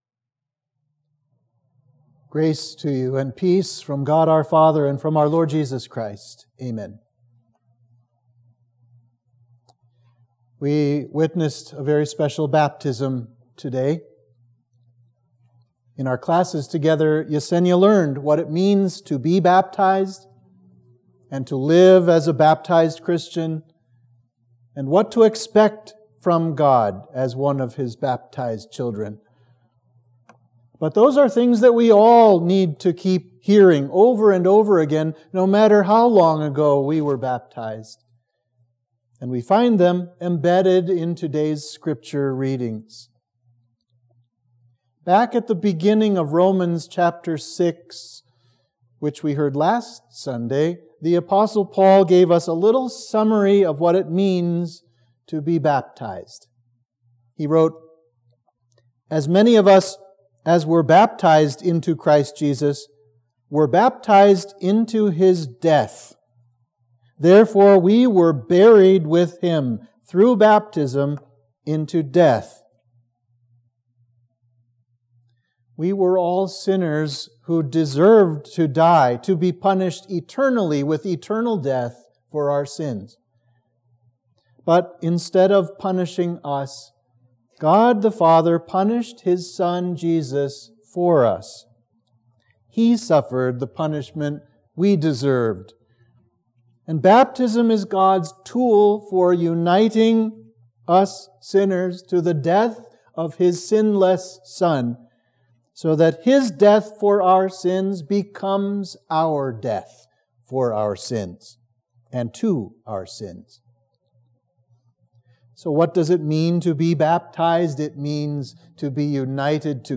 Sermon for Trinity 7